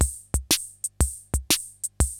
CR-68 LOOPS1 4.wav